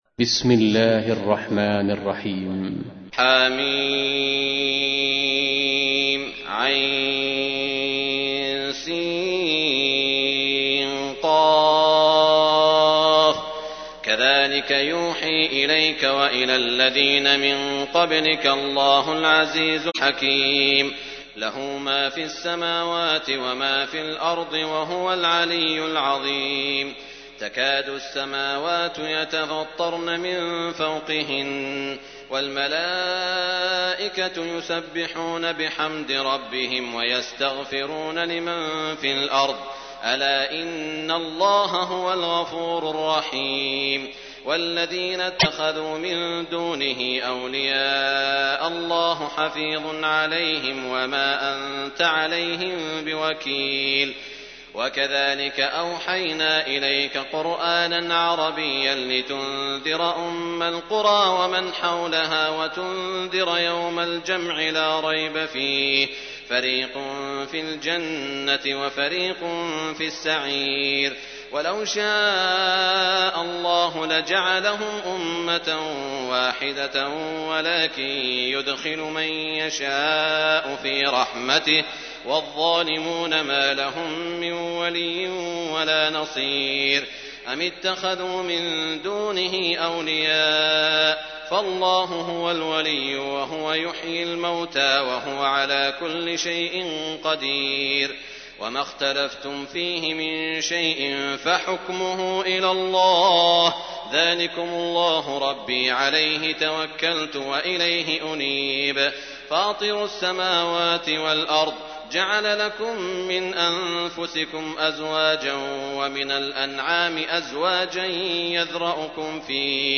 تحميل : 42. سورة الشورى / القارئ سعود الشريم / القرآن الكريم / موقع يا حسين